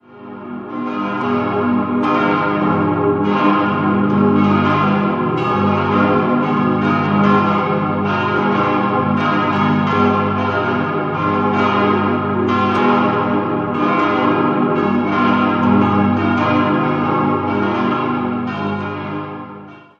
In den Jahren 1899 bis 1911 konnte die reformierte Bevölkerung ein eigenes Gotteshaus errichten. Zuvor wurde die alte Kirche über Jahrhunderte gemeinsam genutzt. 5-stimmiges As-Dur-Geläut: as°-c'-es'-f'-as' Alle Glocken wurden im Jahr 1910 von der Firma Rüetschi in Aarau gegossen.